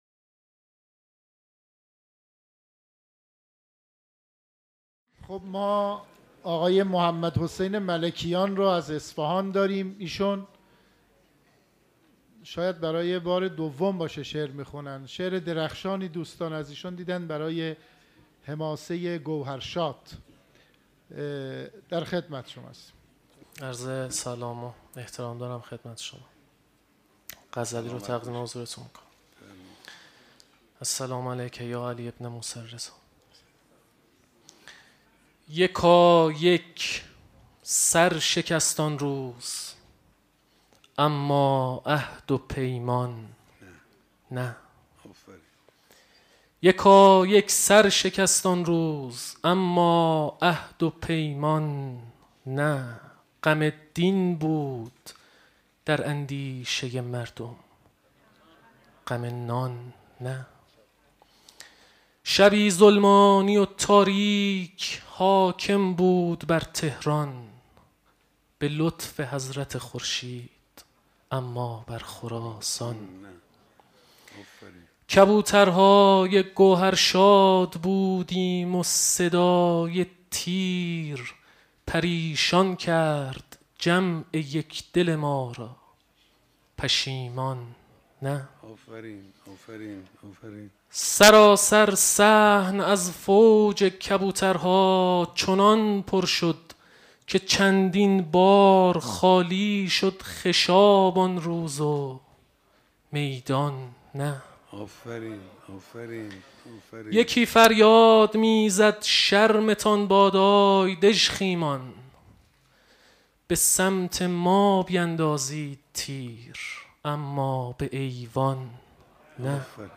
شعر خوانی | یکایک سر شکست آن روز اما عهد و پیمان، نه